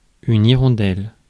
Ääntäminen
IPA: /i.ʁɔ̃.dɛl/